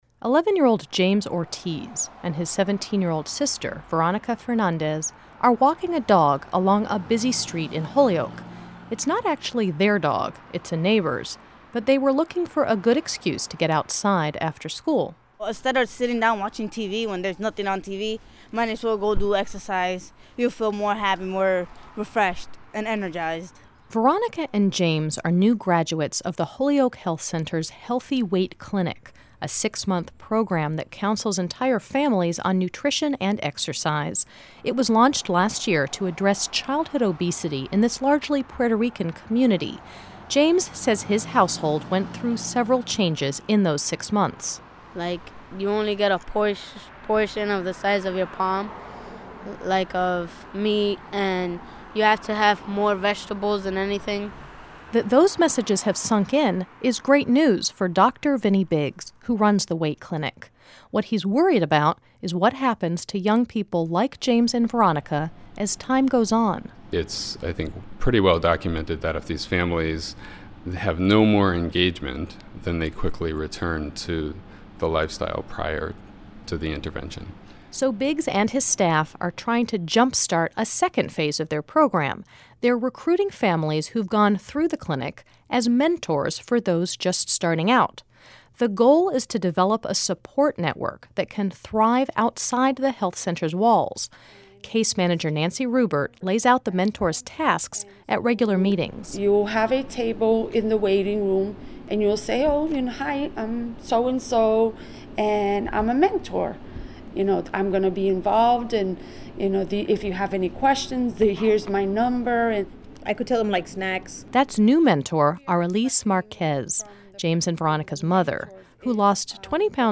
This series first aired on WFCR in July, 2010